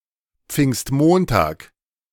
In Germany, Whit Monday (German: Pfingstmontag [p͡fɪŋstˈmoːntaːk]
De-Pfingstmontag.ogg.mp3